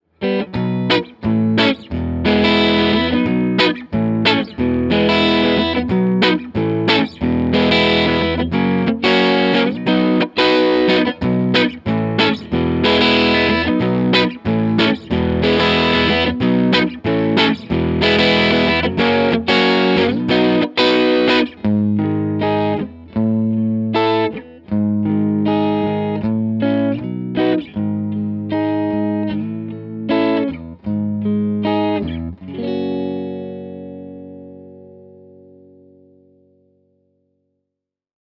Mic was a single SM57, to Vintech (Neve-style) pre, to Apogee Rosetta 200 A/D, to the computer.
Ch.1 Clean was as follows - no MV, cut 3:00, vol 7:30, contour pos 2 (from left), munch/hi on the back, Lo input on the front.
TC15_Ch1_Clean_SD59_Neck.mp3